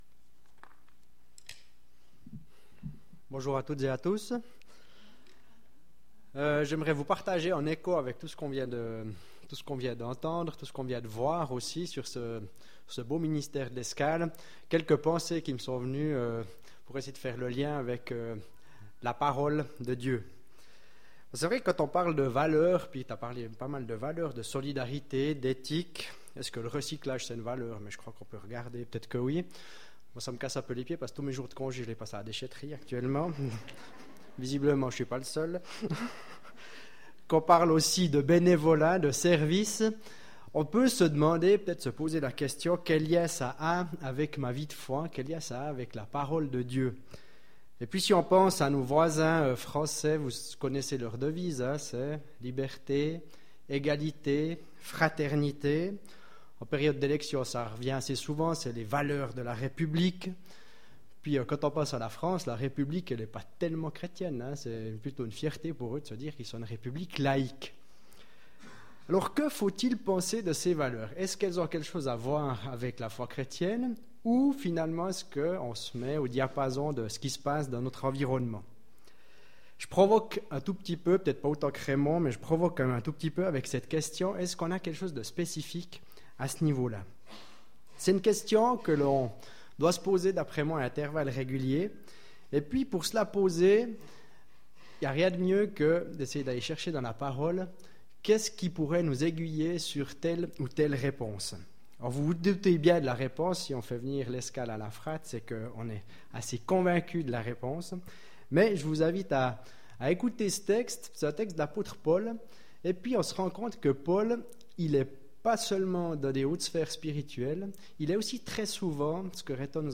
Méditation du 2 avril 2017